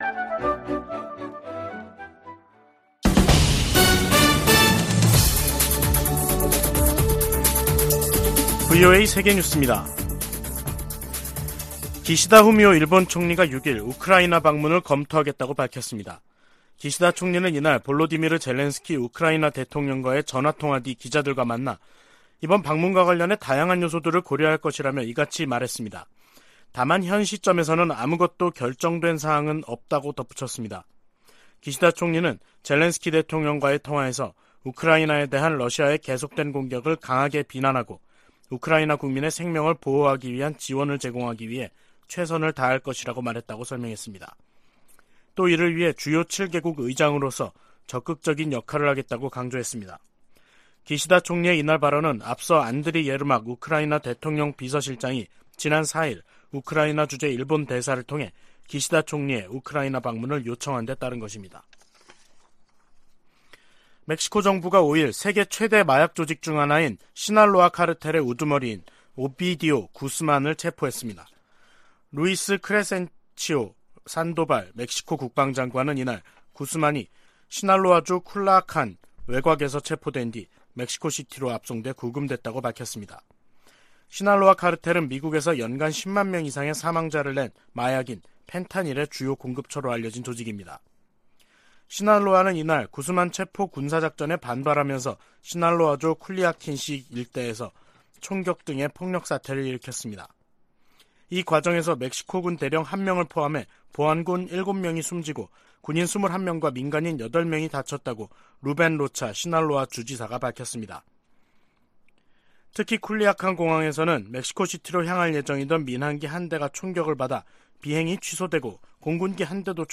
VOA 한국어 간판 뉴스 프로그램 '뉴스 투데이', 2023년 1월 6일 3부 방송입니다. 미국과 일본이 워싱턴에서 외교 국방장관 회담을 개최한다고 미 국무부가 발표했습니다. 미 국방부가 북한의 핵탄두 보유량 증대 방침에 대한 우려를 표시했습니다.